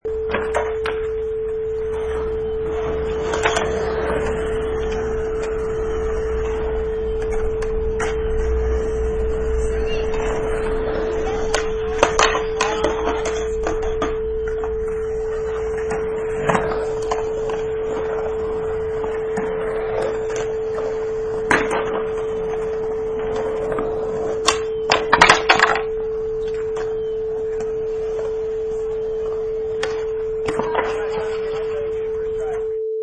Sound: Skate Park 4
Sounds of a skate park
Product Info: 48k 24bit Stereo
Category: Sports / Skateboarding
Try preview above (pink tone added for copyright).
Skate_Park_4.mp3